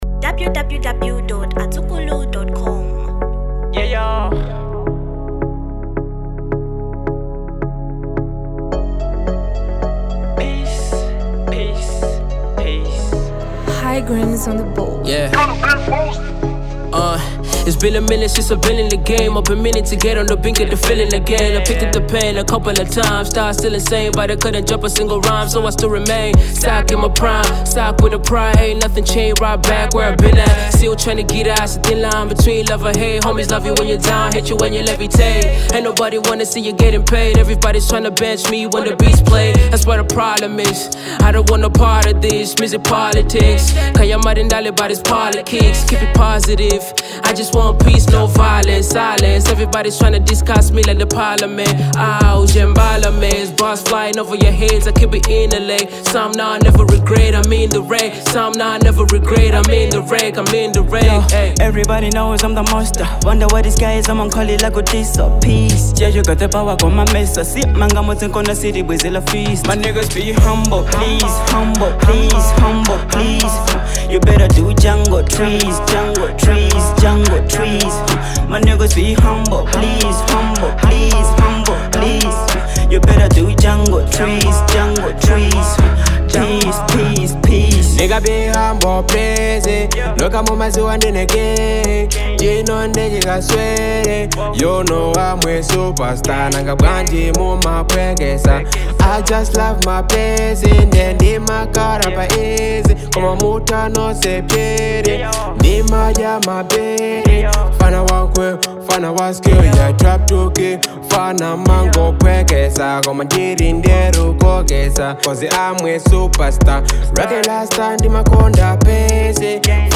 Genre Hip-hop